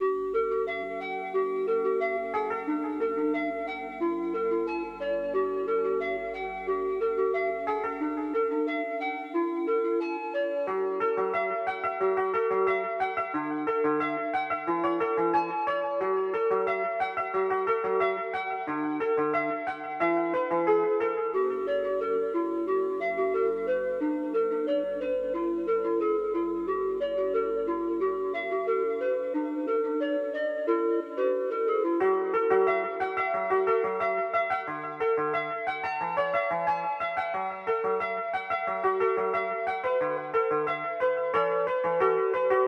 Something small and new.Constuctive feedback appreciated as I don't have much experience with mysterious vibes.